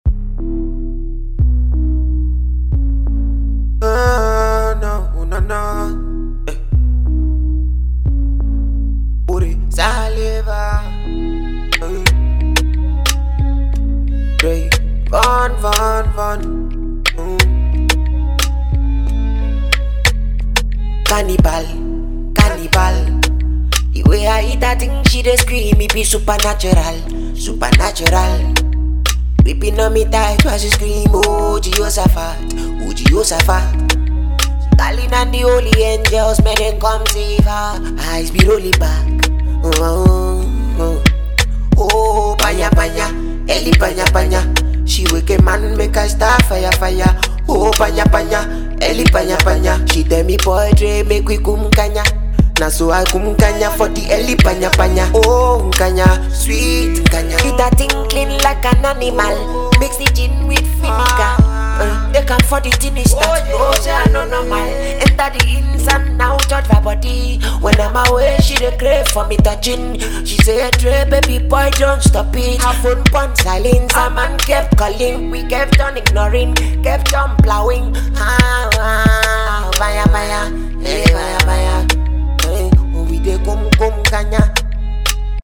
mid tempo afro fusion jam